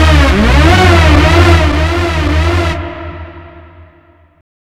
OLDRAVE 3 -R.wav